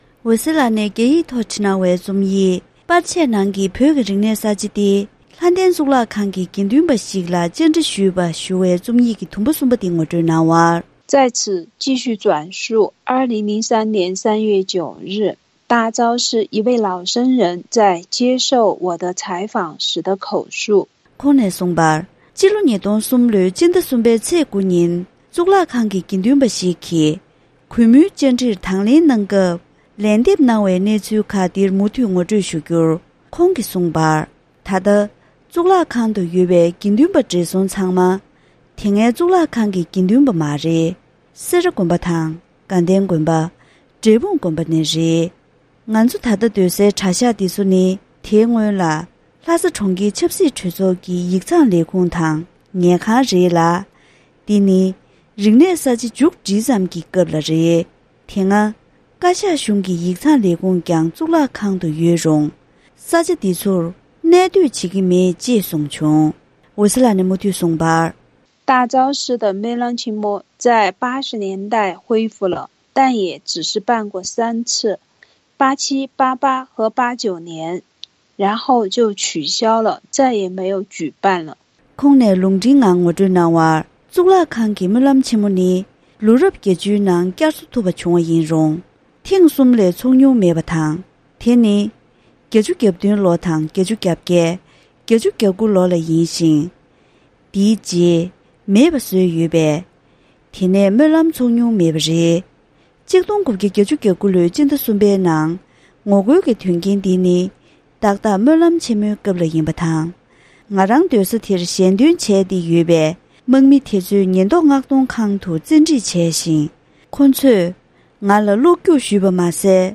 གཙུག་ལག་ཁང་གི་དགེ་འདུན་པ་ཞིག་ལ་བཅར་འདྲི་ཞུས་པ།